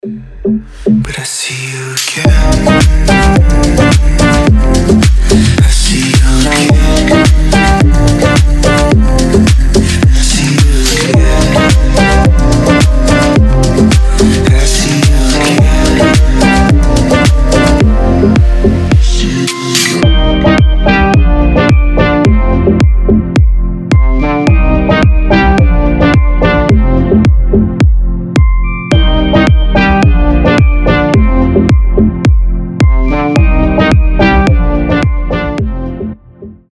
• Качество: 320, Stereo
deep house
мелодичные
чувственные
ремиксы